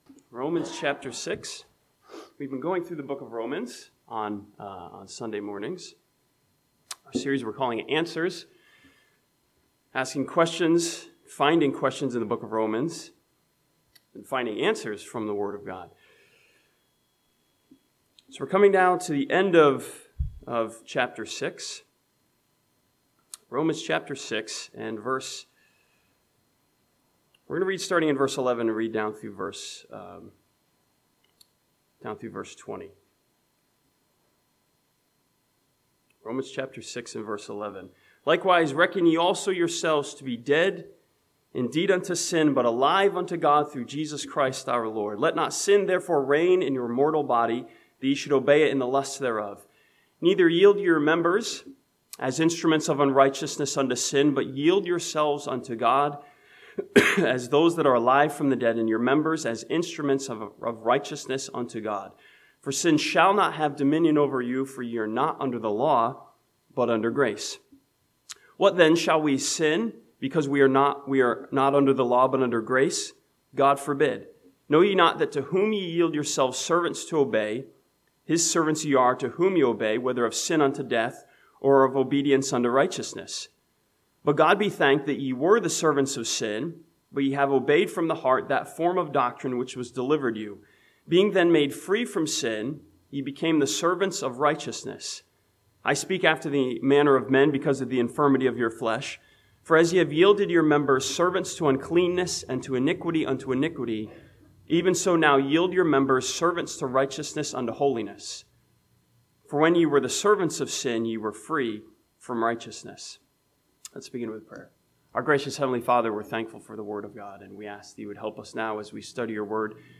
This sermon from Romans chapter 6 challenges us with a question of service, "who are you serving?"